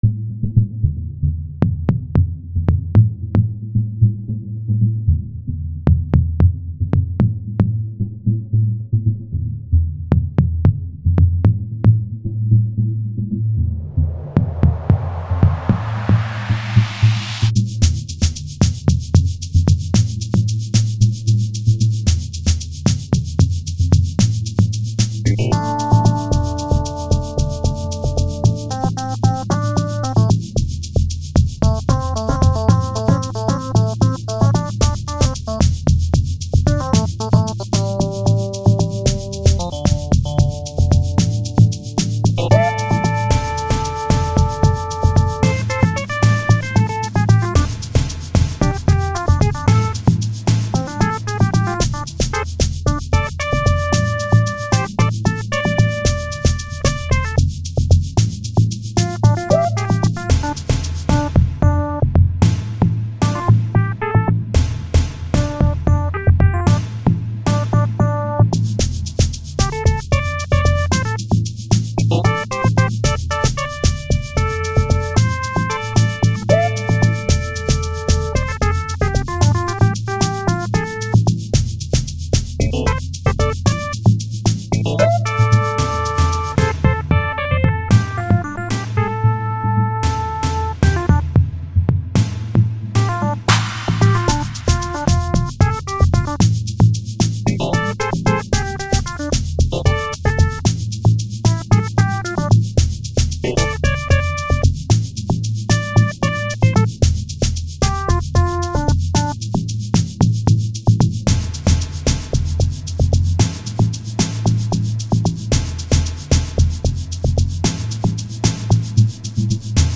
05:42 Genre : Amapiano Size